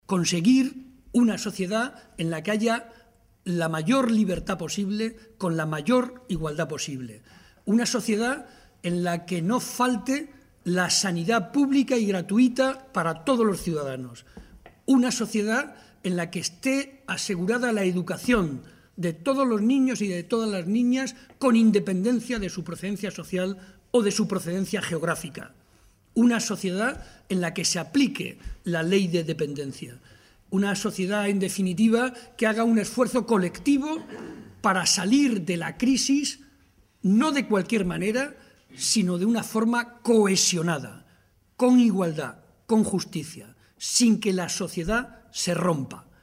Momento de la rueda de prensa.